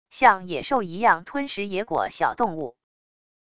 像野兽一样吞食野果小动物wav音频